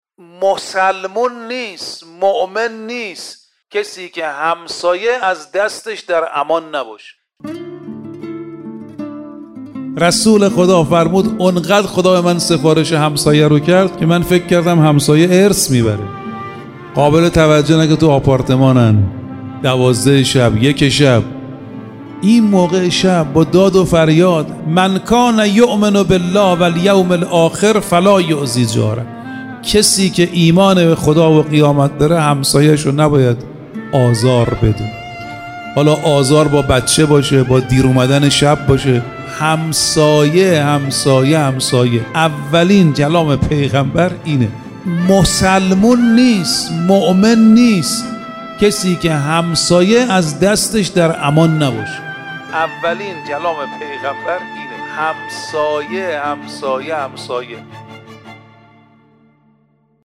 مجموعه سخنرانی های کوتاه و شنیدنی